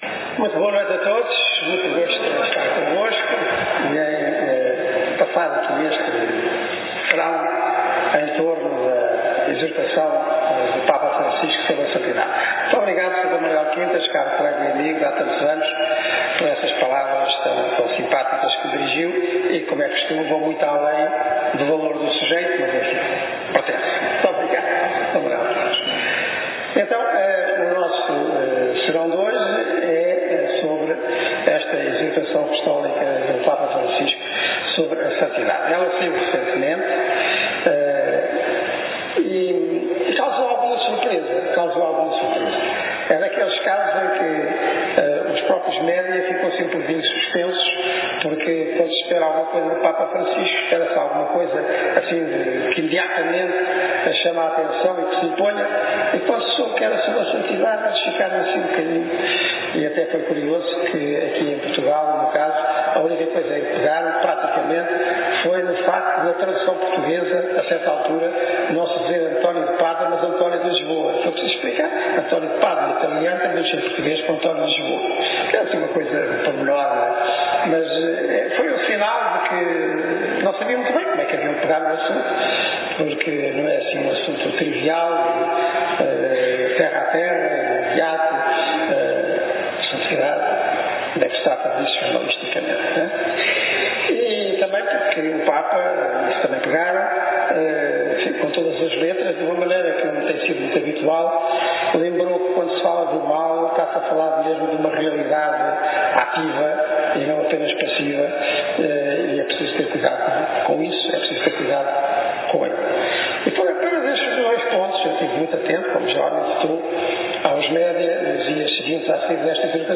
Na apresentação, que contou com cerca de 1250 pessoas, feita no primeiro dia em Faro no salão da paróquia de São Luís e no segundo em Portimão na igreja matriz, D. Manuel Clemente deixou claro que a finalidade do documento é a santificação de cada pessoa porque “Deus tem um projeto” para cada uma.
Conferencia_d_manuel_clemente.mp3